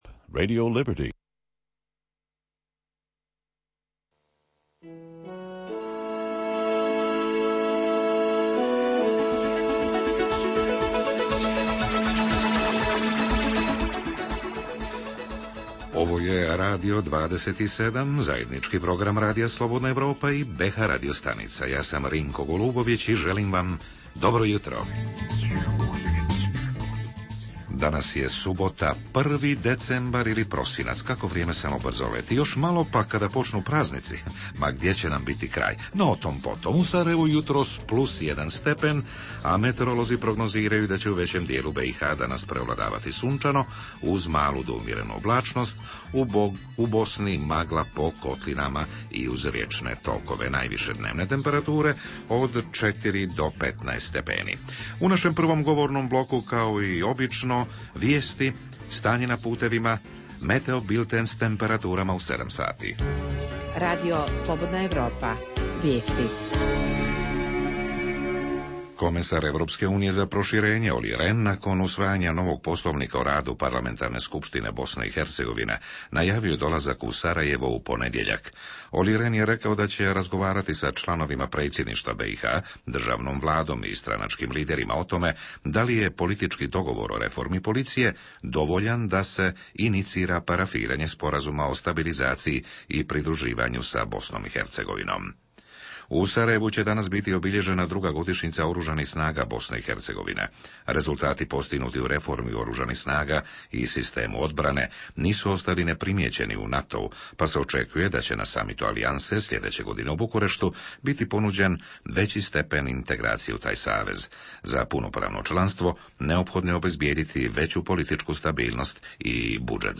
Osim vijesti 8.00, 8.30 i 8.55, u jutru Radija 27 za 1. decembar/prosinac objavljujemo: izvještaj iz Bijeljine o poplavama u Semberiji;